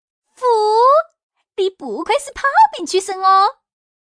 Index of /hunan_master/update/12814/res/sfx/changsha_woman/